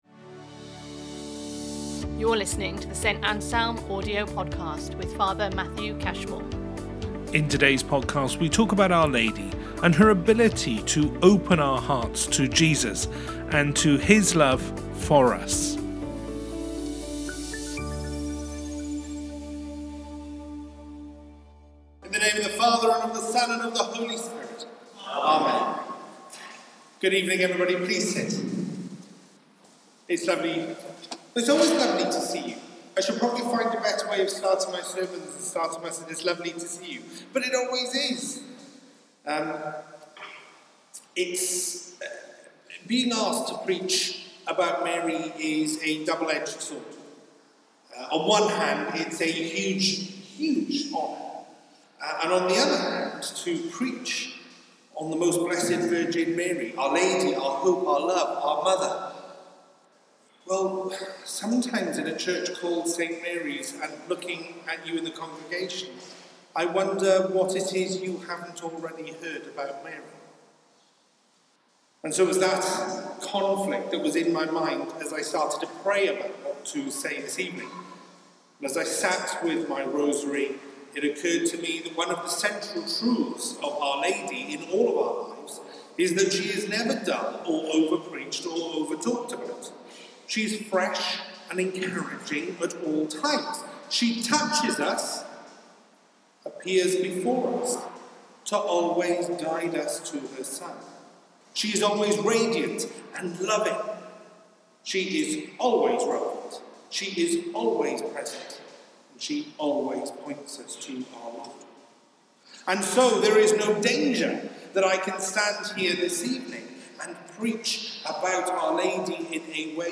HomilyOfOurLady.mp3